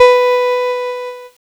Cheese Note 05-B2.wav